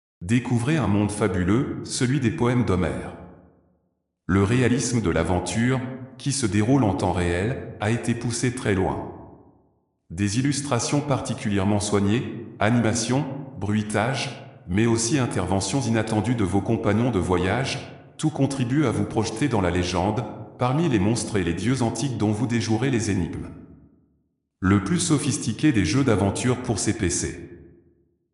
1001-B.C.-A-MEDITERRANEAN-ODYSSEY-Voix.mp3